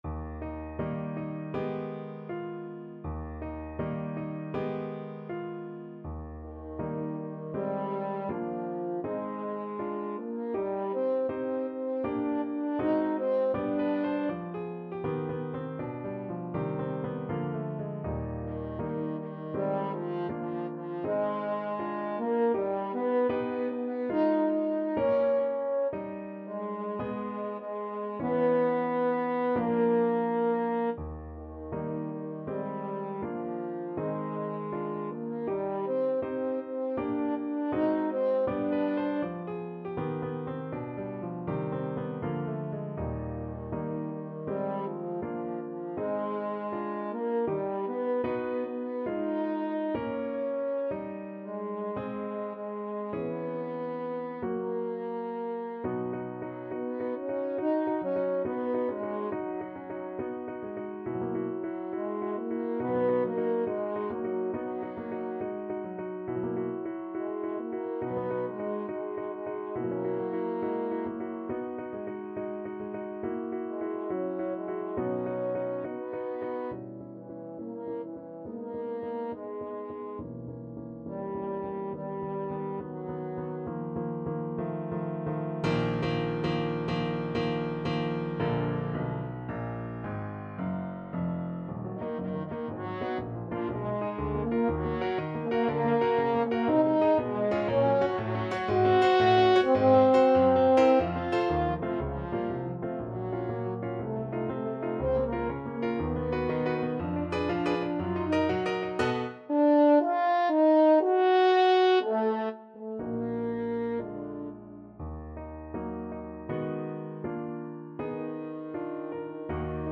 2/4 (View more 2/4 Music)
Moderato =80
Classical (View more Classical French Horn Music)